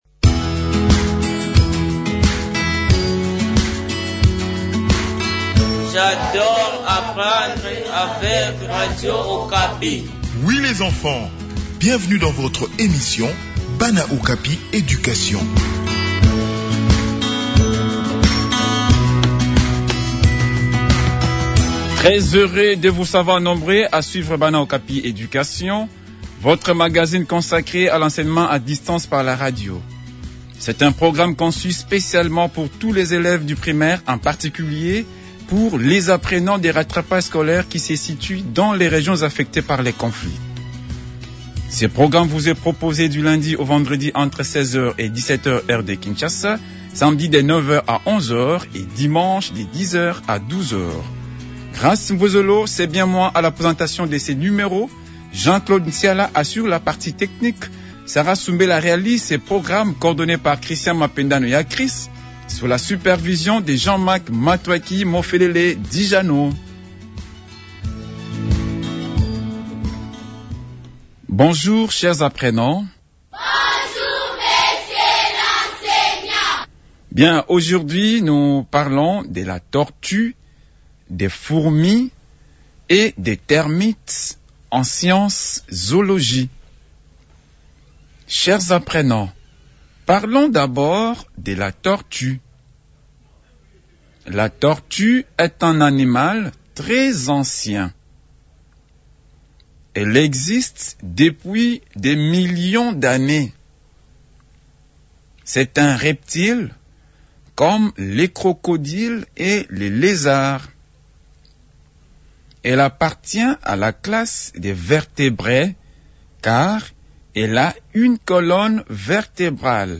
Le numéro de Bana Education de ce jour vous propose une leçon sur les tortues, les fourmis et les termites.